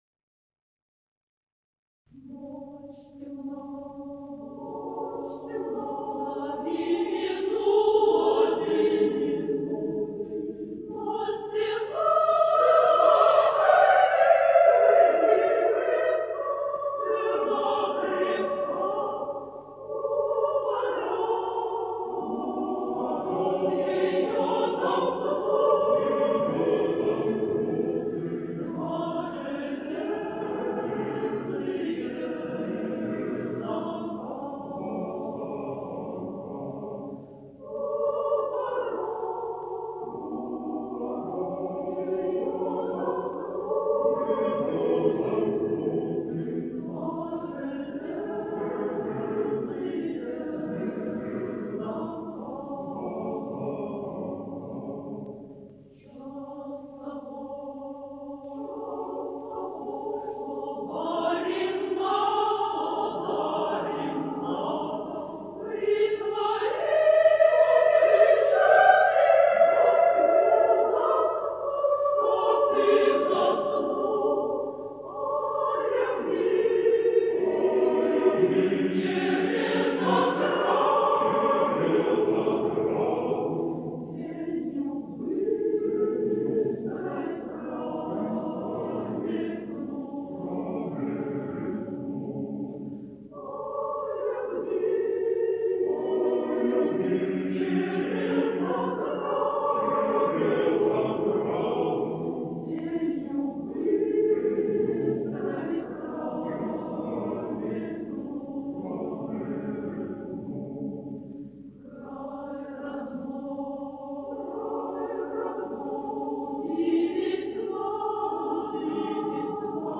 Песня считается революционной